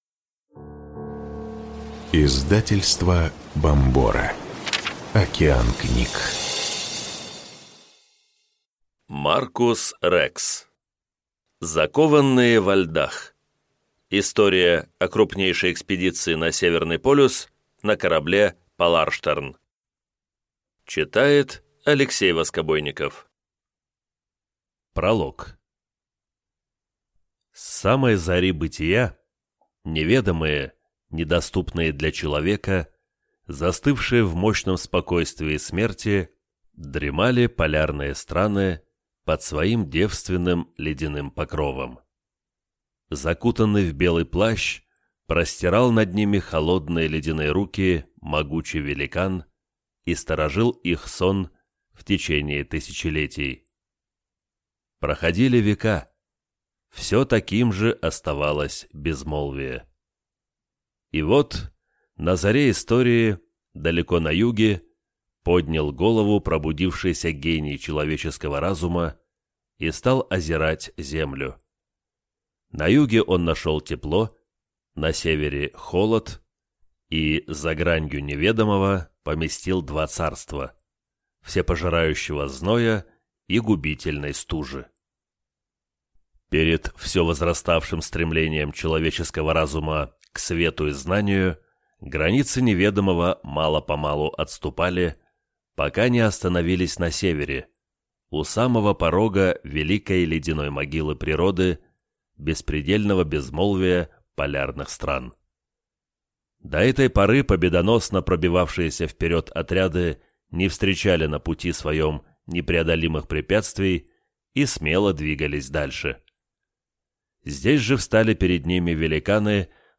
Аудиокнига Закованные во льдах. История о крупнейшей международной экспедиции на Северный полюс на корабле «Поларштерн» | Библиотека аудиокниг